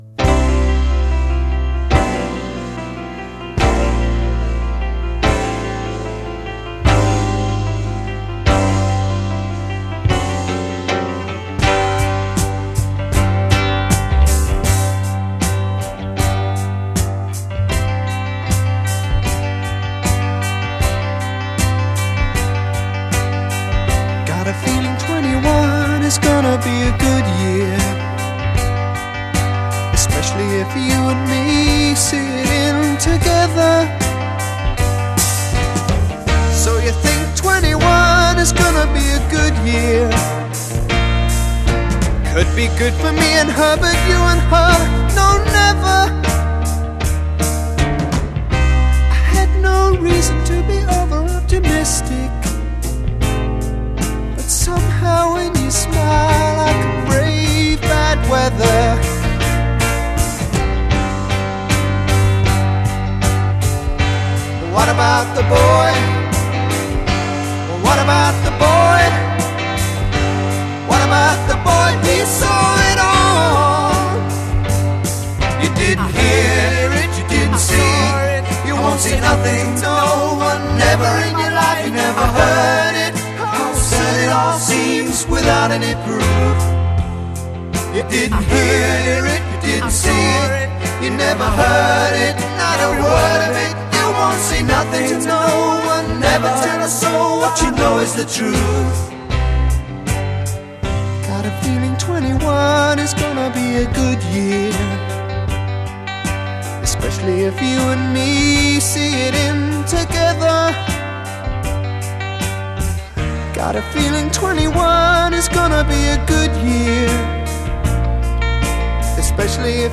Recorded at I.B.C. Studios, London
A   Lover: guitar picks up repeated figure; solo voice a
C   Mother: vocal duet over pedal tone c
two voices proclaim, the other responds; rising bass line d